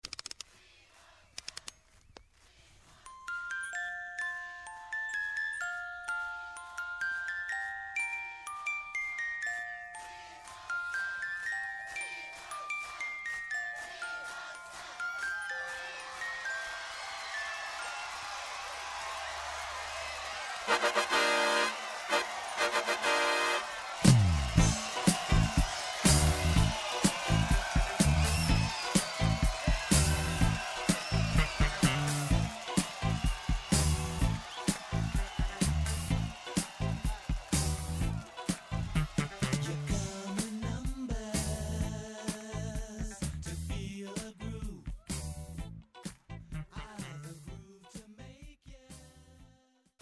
ネジを巻く音、オルゴールの
のメロディー、そして観客の歓声から始まるヒット曲